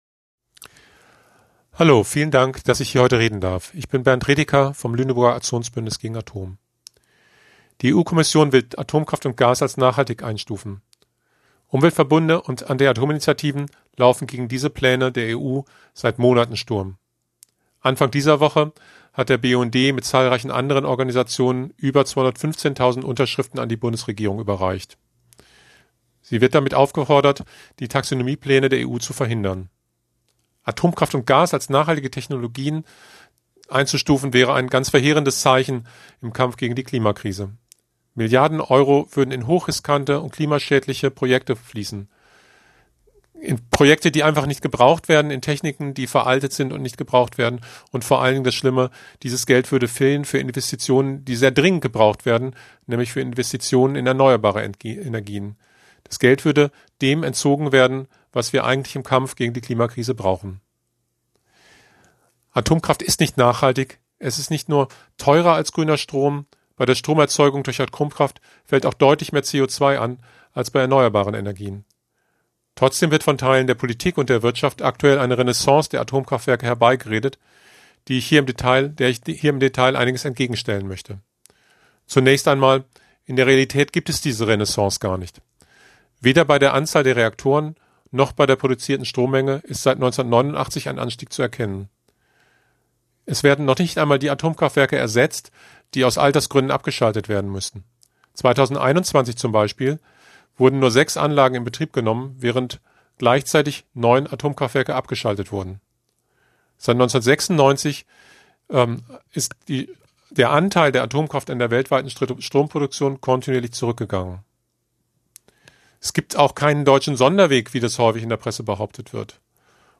Unten findet ihr einzelne der Redebeiträge auf der Kundgebung.